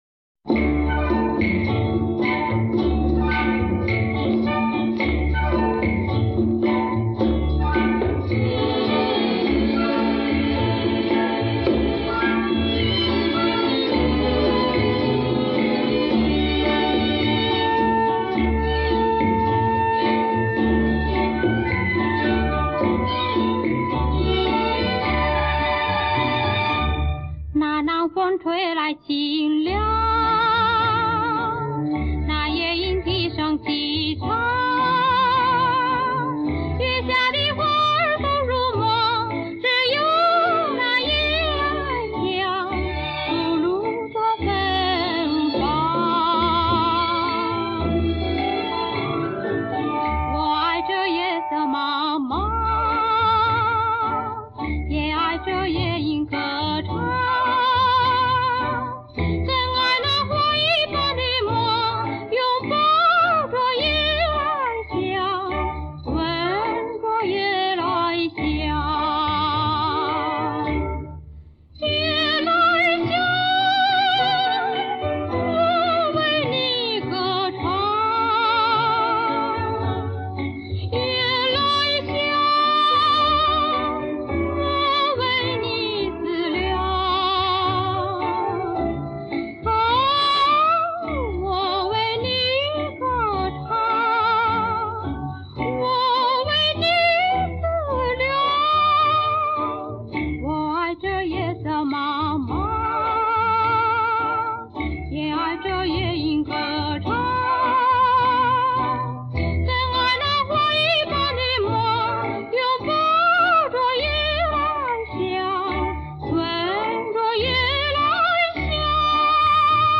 国语经典名曲
永垂不朽的巨星金嗓原音重现